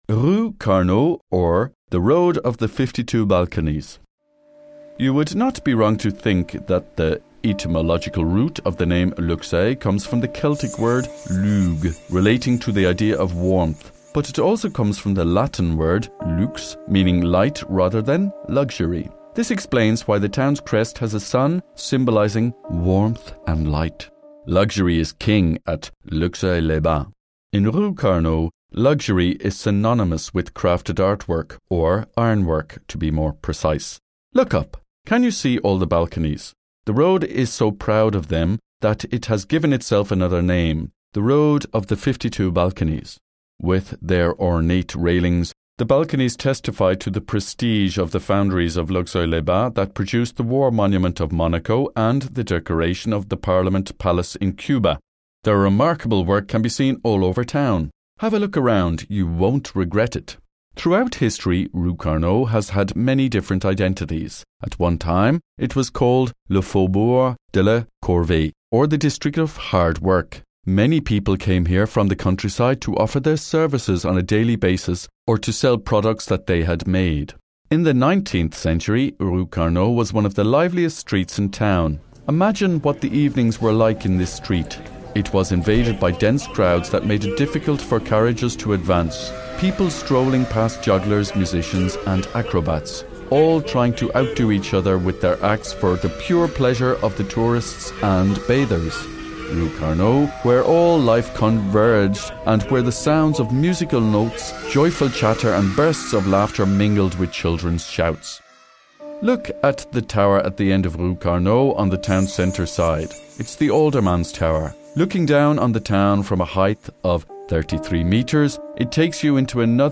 Balade audio – 02 La rue Carnot ou la rue aux 52 balcons
Explications audio